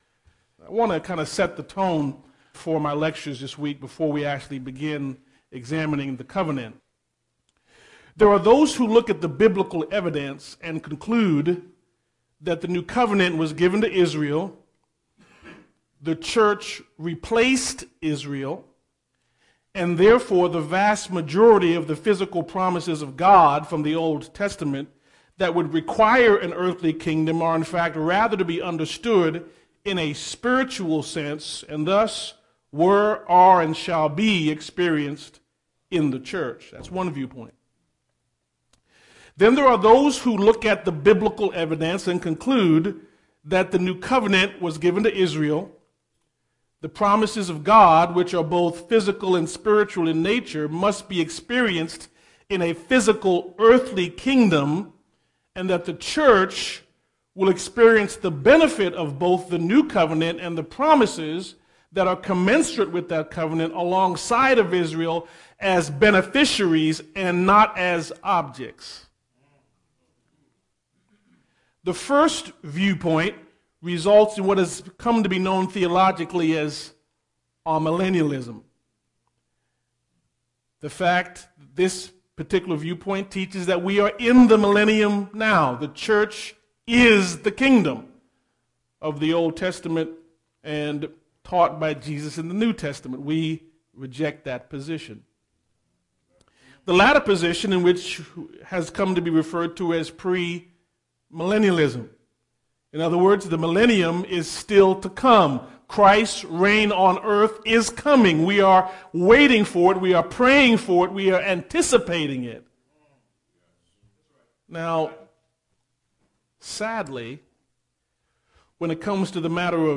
The messages on this page are conference messages preached primarily of the Sovereign Grace Bible Conference in Mesquite, Tx.